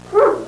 Barking Dog Sound Effect
Download a high-quality barking dog sound effect.
barking-dog-2.mp3